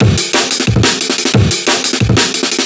amen_4_a_saturated.wav